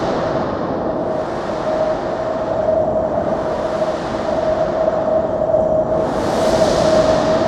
sfx_skill 16_1.wav